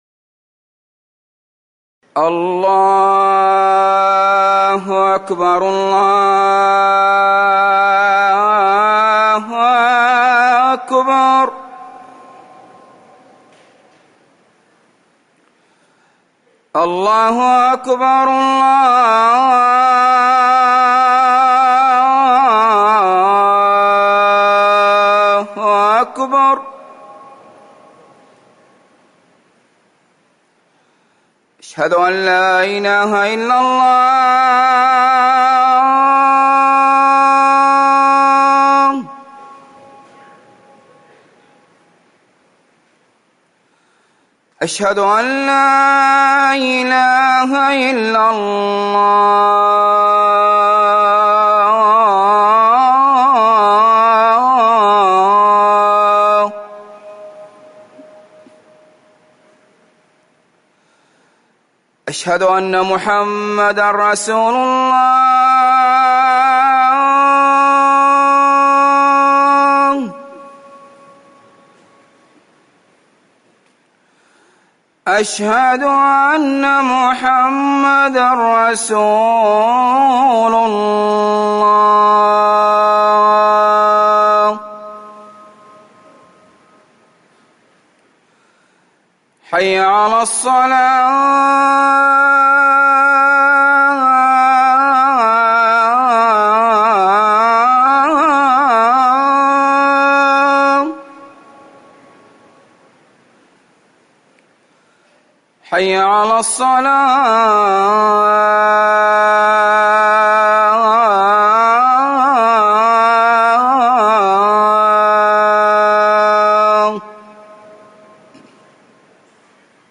أذان الجمعة الثاني
تاريخ النشر ٥ صفر ١٤٤١ هـ المكان: المسجد النبوي الشيخ